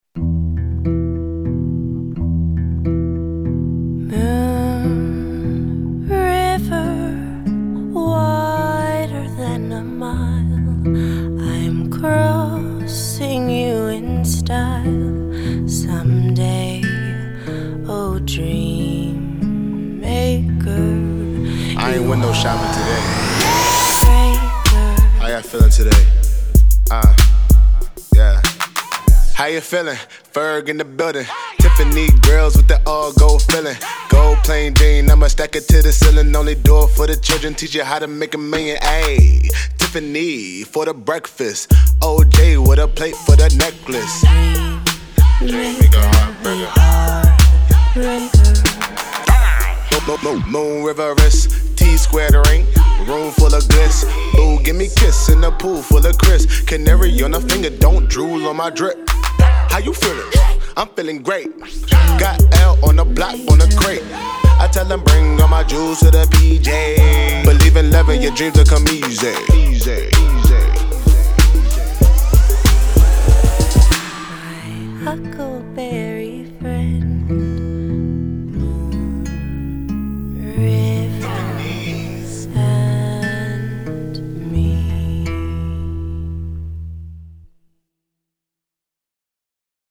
SINGLESHIP-HOP/RAP